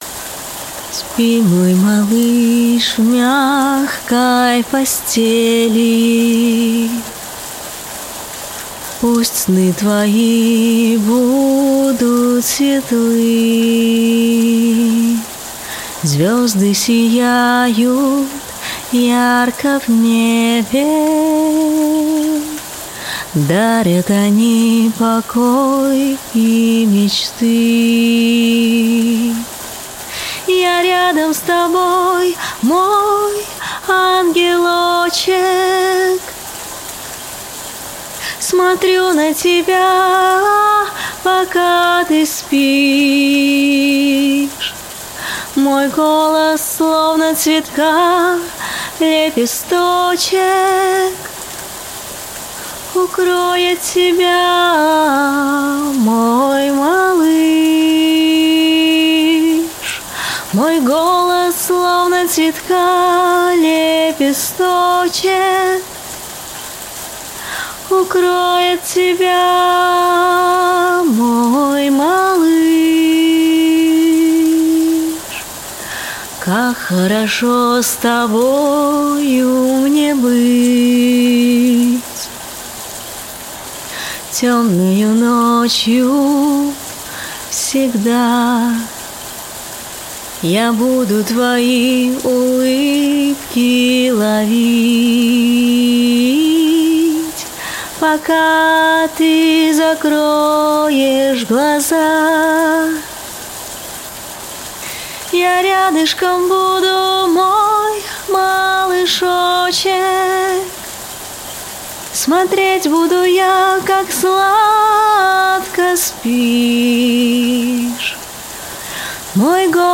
🎶 Детские песни / Колыбельные песни